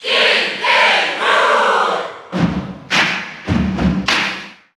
Category: Crowd cheers (SSBU) You cannot overwrite this file.
King_K._Rool_Cheer_German_SSBU.ogg